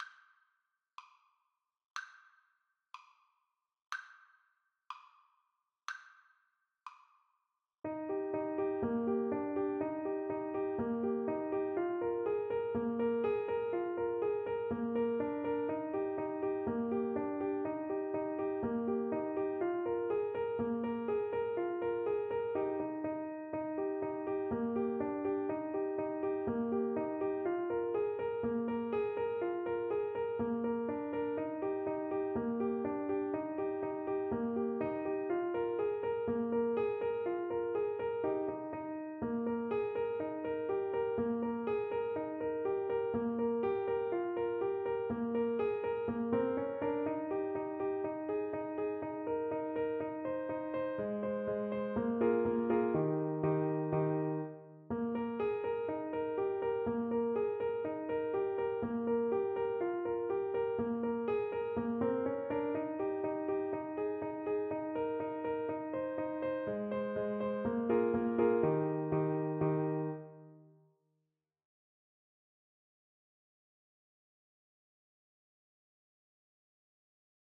Clarinet
Eb major (Sounding Pitch) F major (Clarinet in Bb) (View more Eb major Music for Clarinet )
Traditional (View more Traditional Clarinet Music)
Hornpipes for Clarinet
Pieces in 2-4 Time Signature